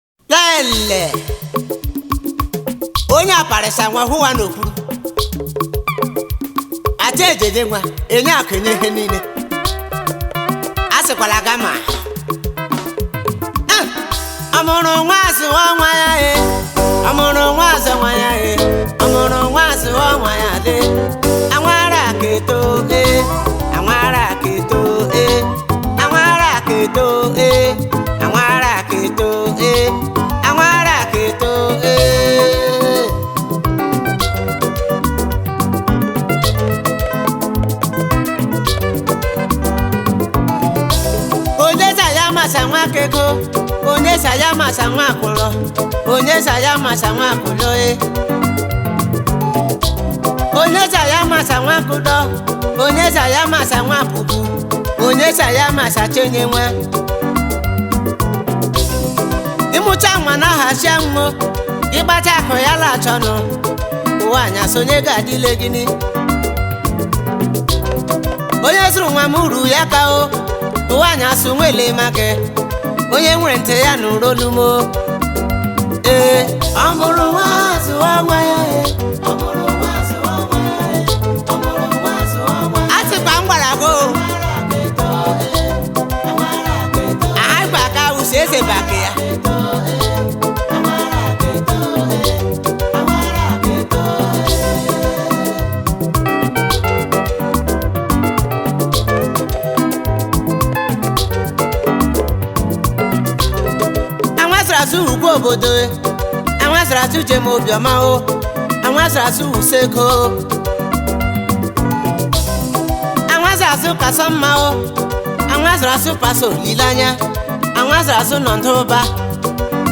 highlife track
highlife tune